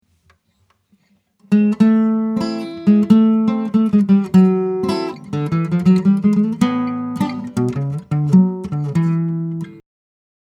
combine chords and single string fills over the four bar chord progression: Am/// D7/// G/// G///